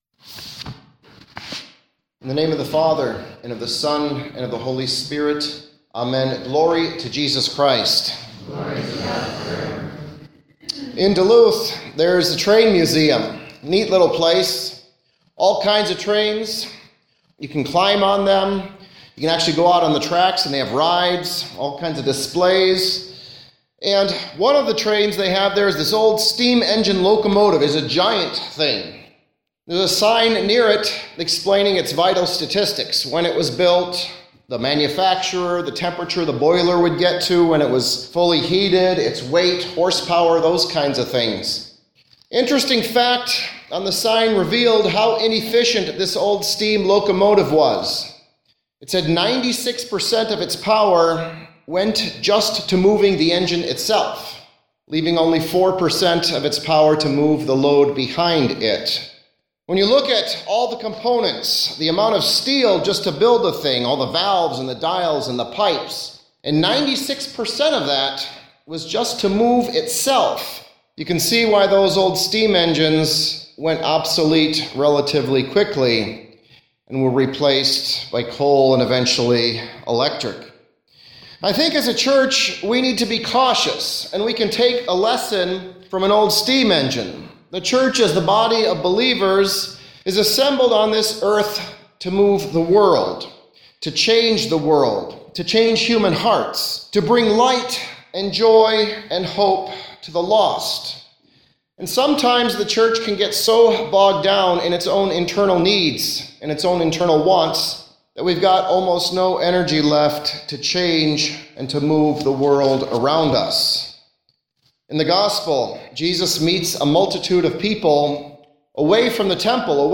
Sermons – 2017